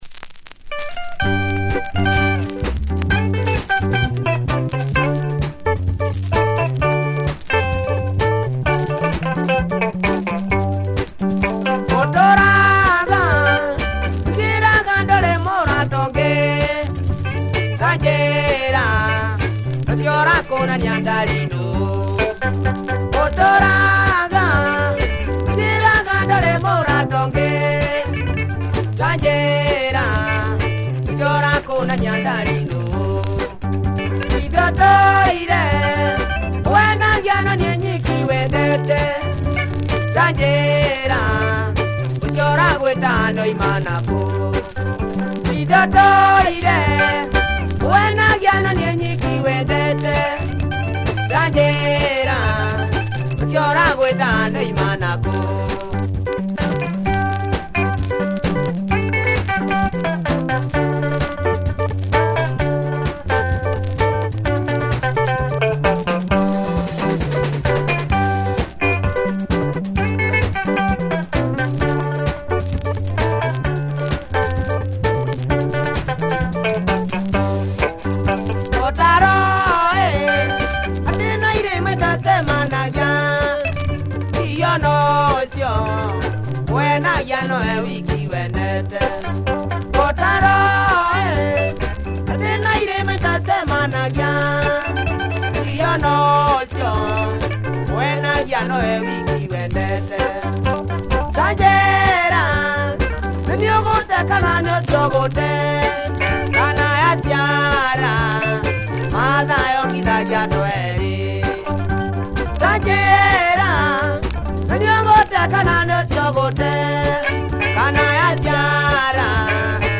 Funky guitar driven
Great solo!